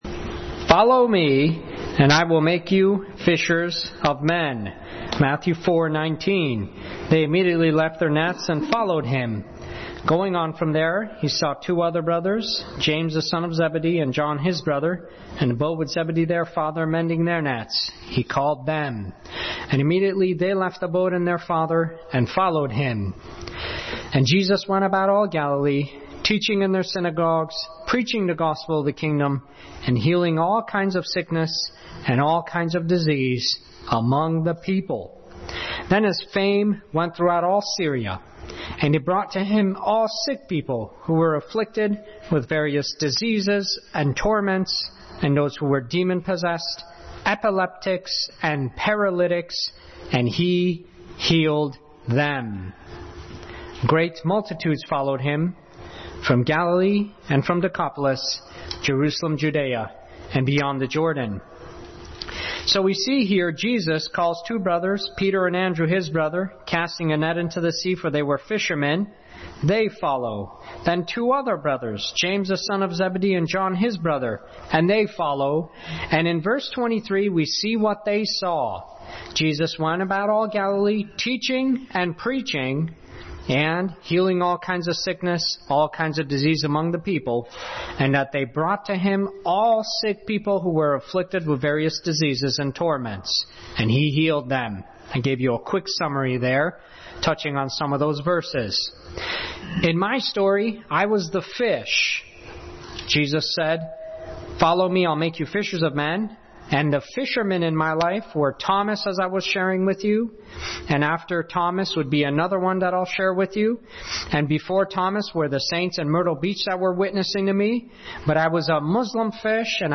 Passage: Matthew 4:18-25 Service Type: Sunday School